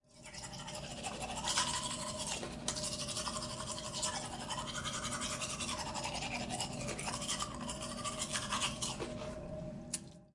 刷牙 双耳
描述：用双耳设置记录刷牙。
Tag: 齿 卫生 牙刷 牙齿 刷涂齿 浴室 刷涂